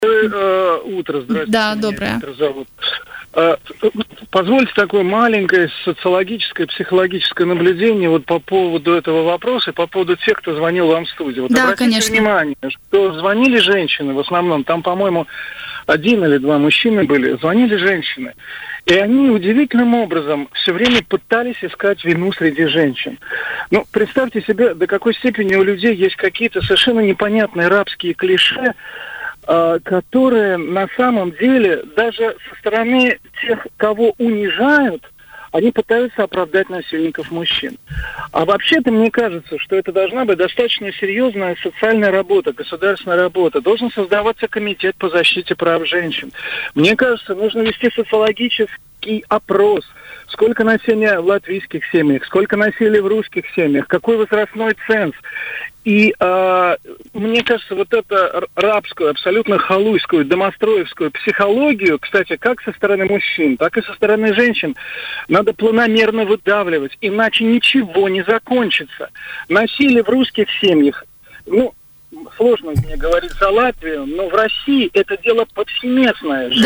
В эфир программы «Утро на Балткоме», в рамках которого обсуждалась тема насилия над женщинами, позвонил слушатель и выразил возмущение «рабской психологией женщин» в латвийском обществе.